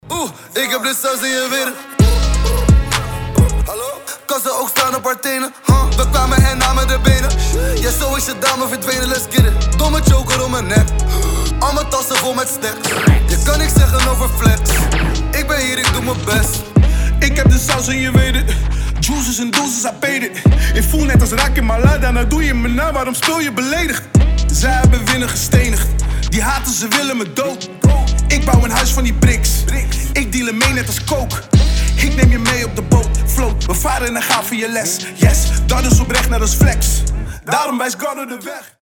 • Качество: 320, Stereo
Хип-хоп
дуэт